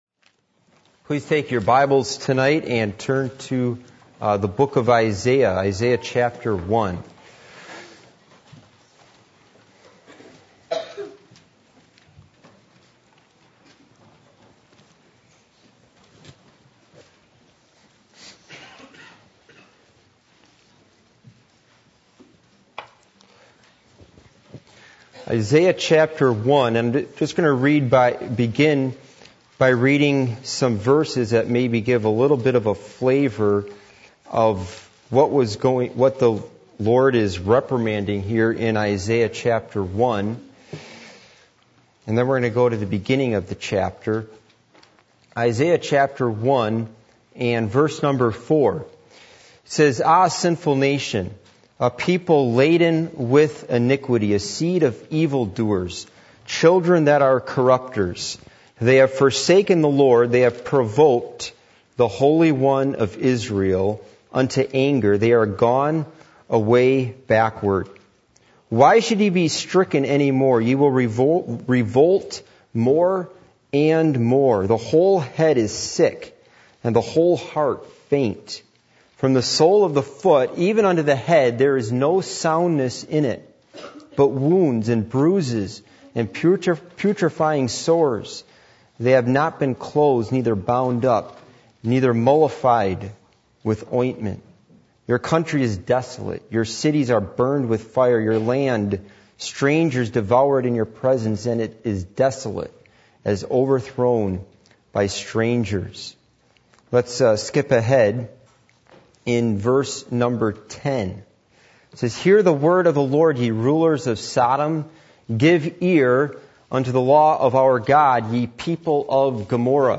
Passage: Isaiah 1:1-31 Service Type: Midweek Meeting %todo_render% « Can God Bless America?